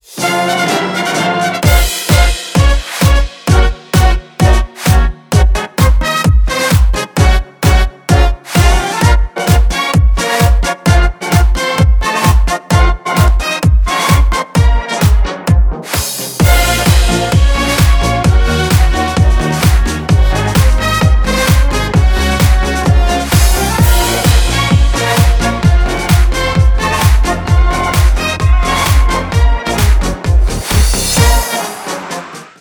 марш
оркестр
классика